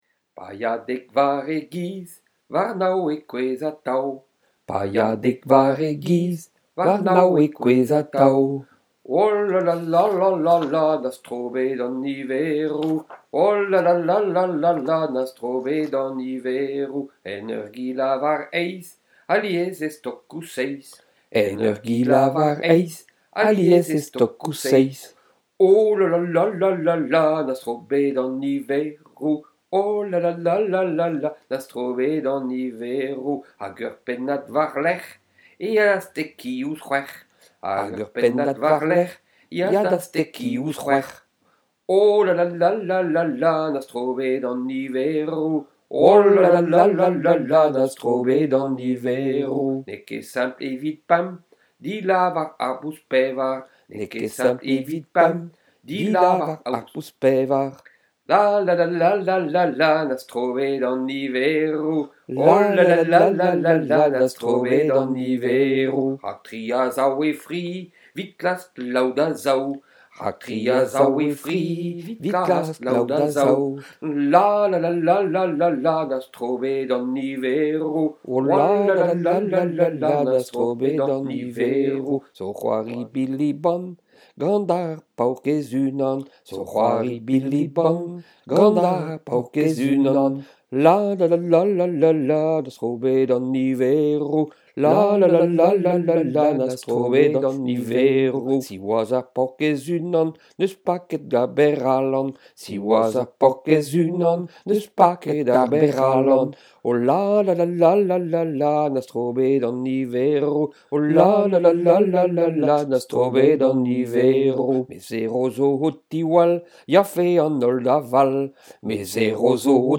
chanteur et amuseur pour les petites et les grands